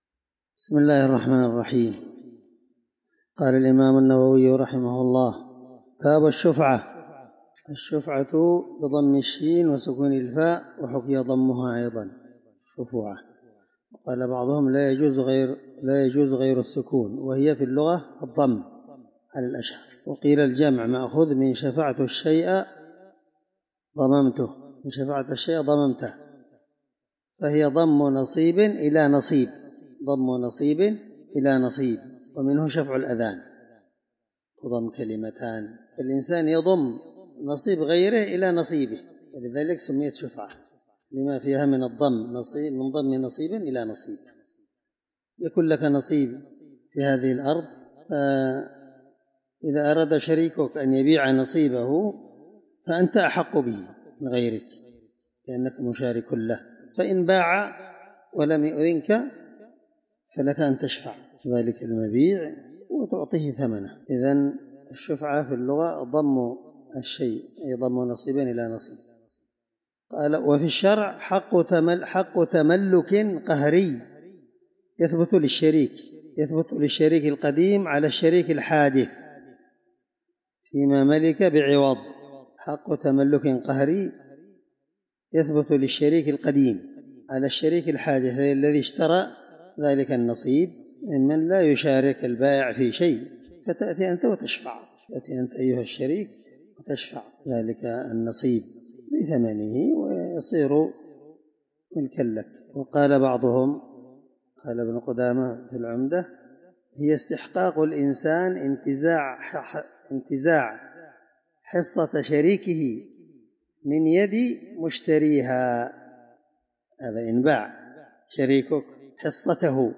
الدرس34من شرح كتاب المساقاة حديث رقم(1608) من صحيح مسلم